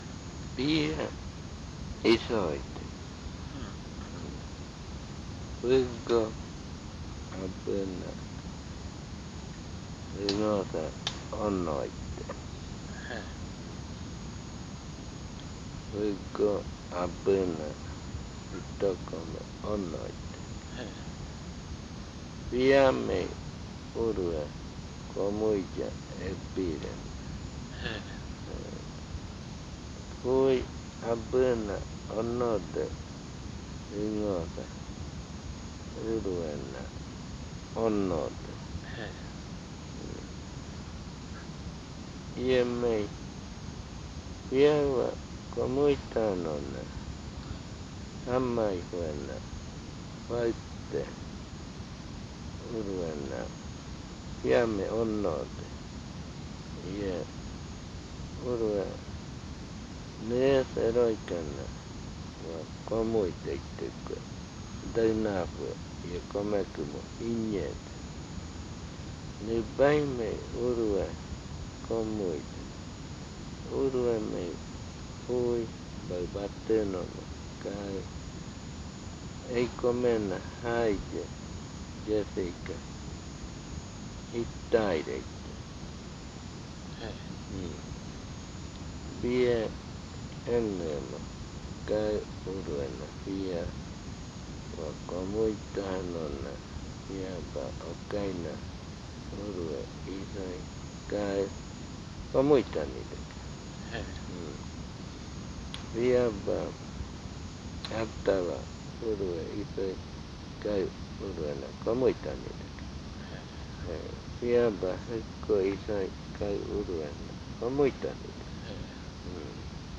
Adofikɨ (Cordillera), río Igaraparaná, Amazonas